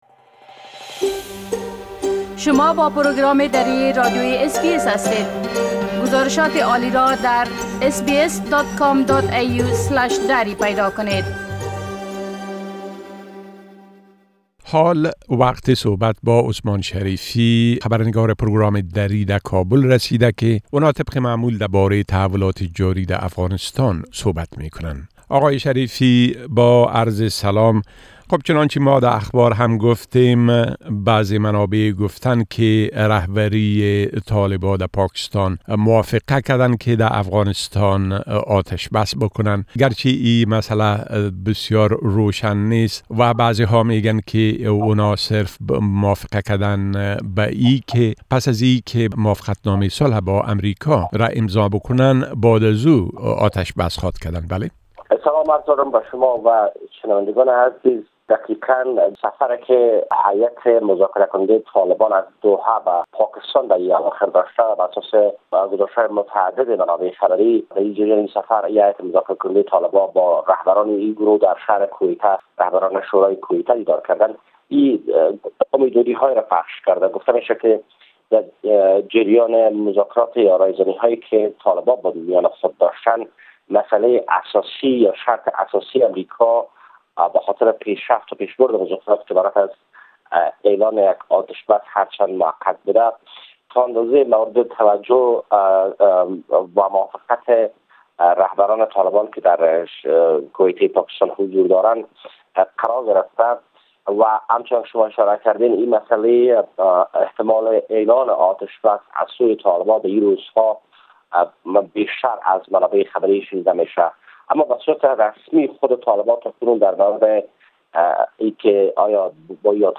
گرازش كامل خبرنگار ما در كابل بشمول اوضاع امنيتى٬ بلند رفتن حجم محصولات زعفران و تحولات مهم ديگر در افغانستان را در اينجا شنيده ميتوانيد.